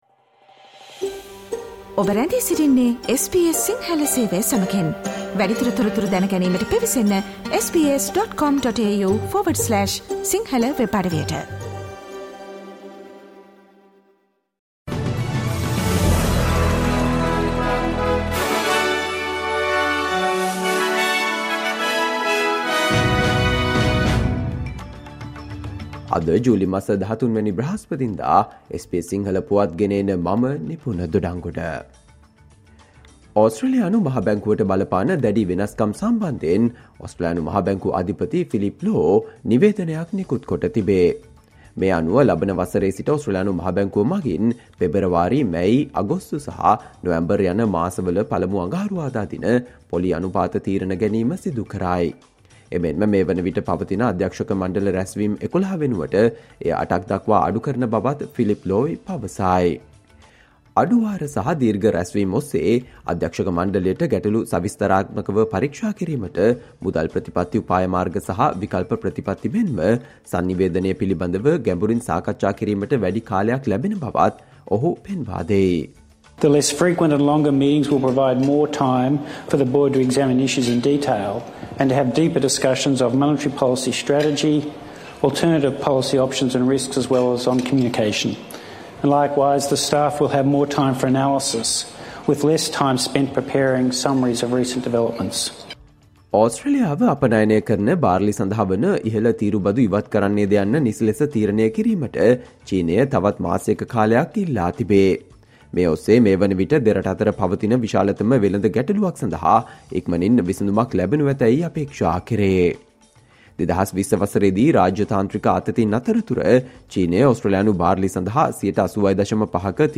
Australia news in Sinhala, foreign and sports news in brief - listen, today - Thursday 13 July 2023 SBS Radio News